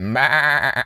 sheep_baa_bleat_02.wav